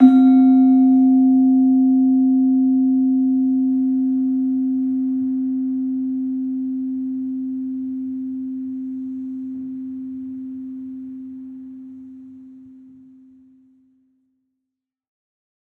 Gamelan